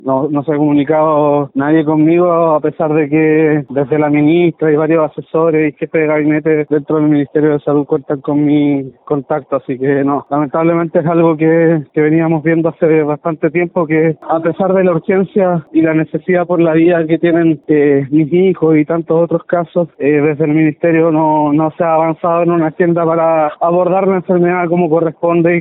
La ministra de Salud, Ximena Aguilera, explicó la negativa para suministrar el fármaco Elevidys para tratar la Distrofia Muscular de Duchenne, argumentando que “no se ha demostrado que tenga evidencia clínica de mejoría”.
ximena-aguilera.mp3